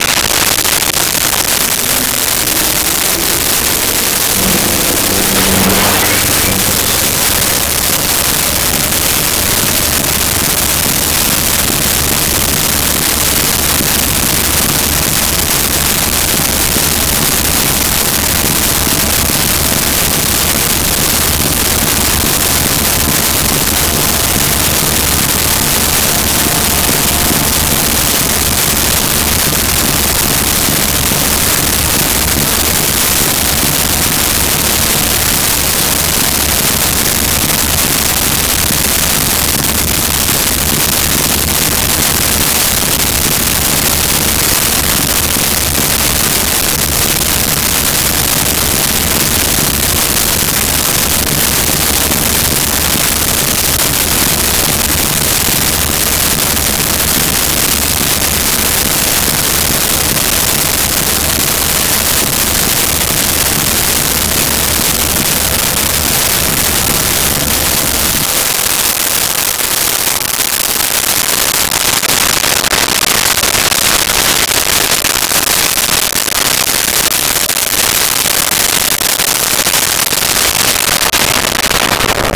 Engine Start Idle Stop
Engine Start Idle Stop.wav